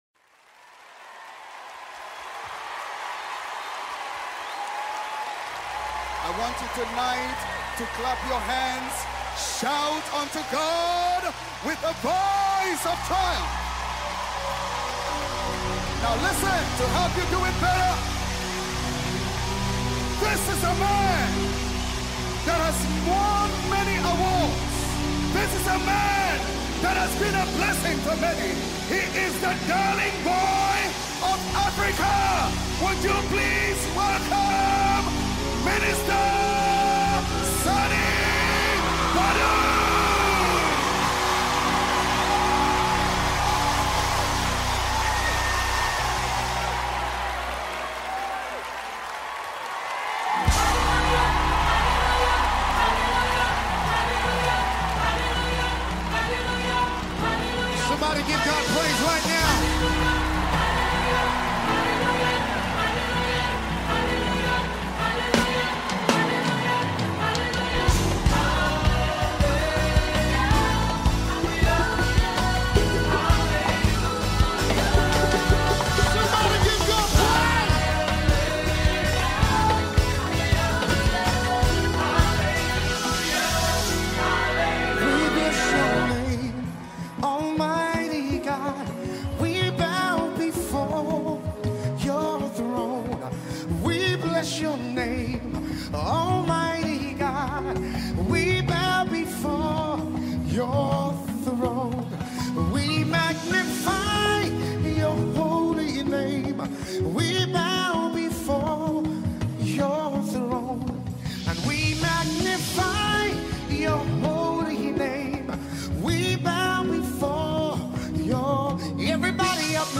Official Live Recording
Recorded Live in MaryLand.